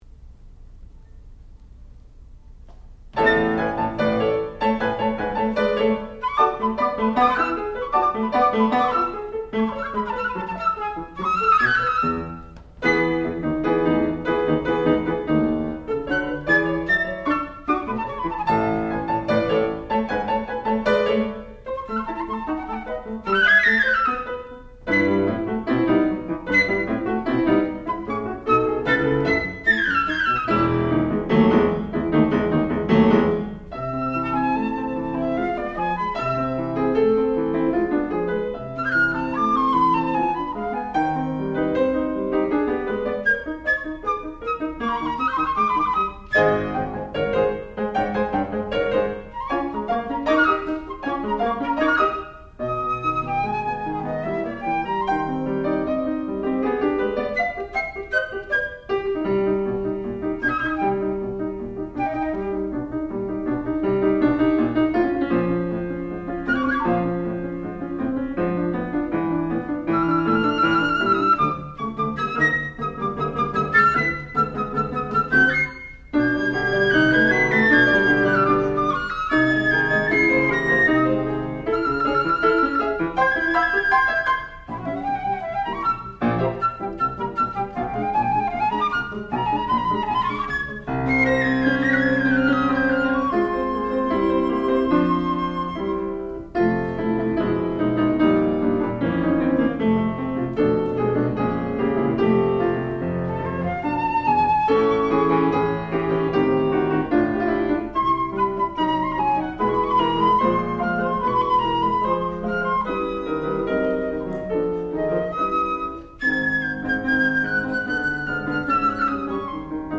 sound recording-musical
classical music
Advanced Recital